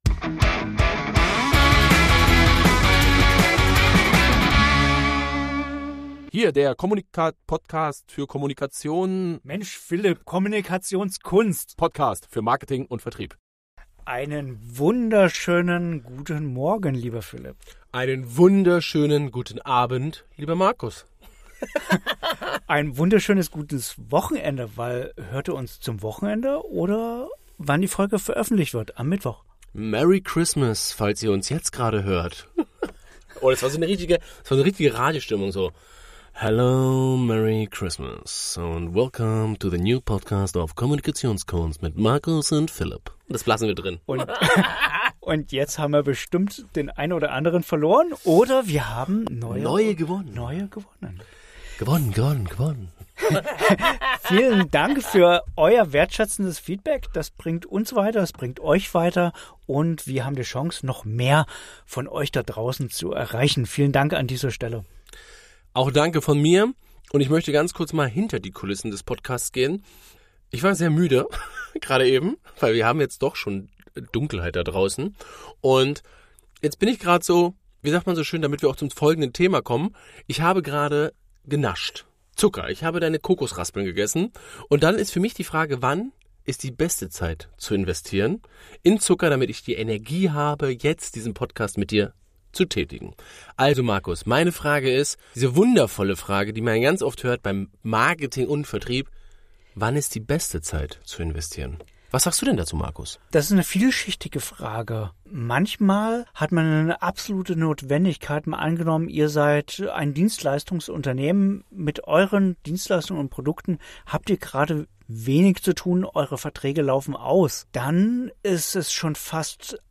Wann ist in eurem Unternehmen, eurer Selbstständigkeit die beste Zeit für Investitionen? Um dieses Thema geht es in unserer heutigen Diskussion.